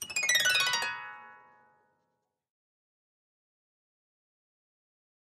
Harp, High Strings Short Descending Gliss, Type 1